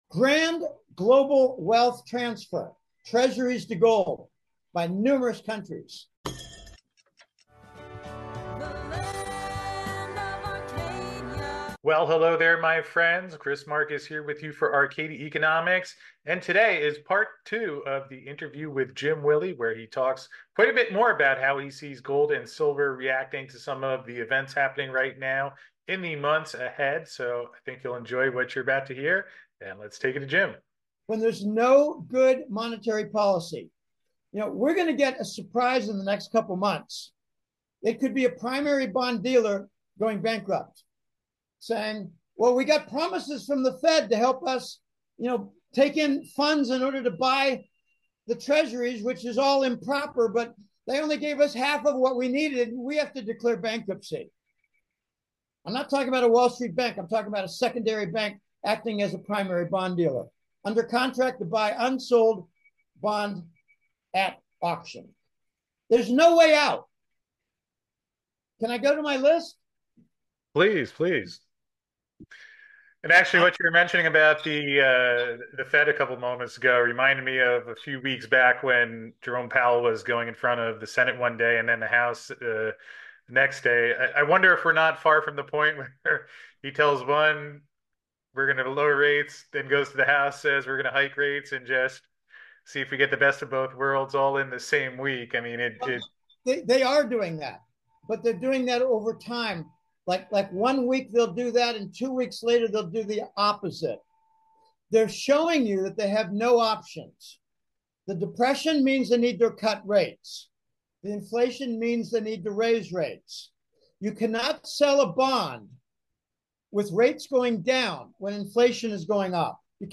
SPREAD THE WORD MP3 Audio Summary ➡ Arcadia Economics in this article discusses how countries are shifting their wealth from treasury bonds to gold due to unstable monetary policies.